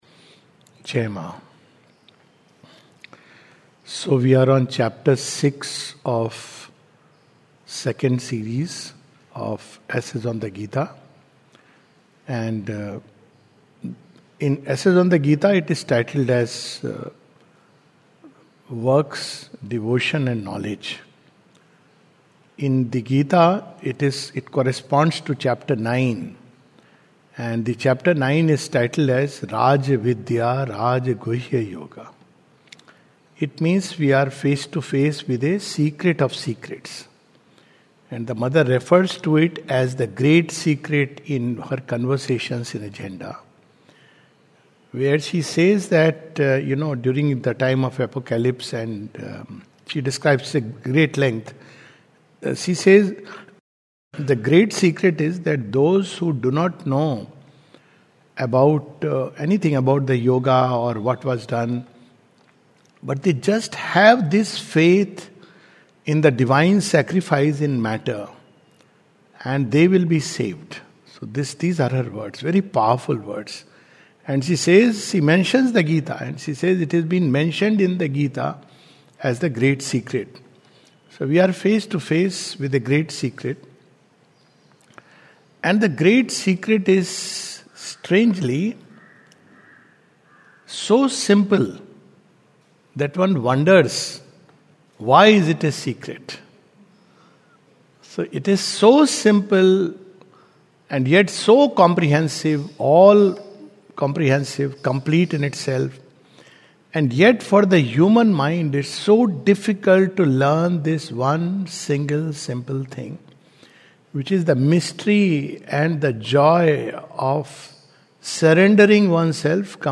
This concludes the summary of Chapter 6 of the Second Series of "Essays on the Gita" by Sri Aurobindo. A talk
recorded on 11th June 2025 at Savitri Bhavan, Auroville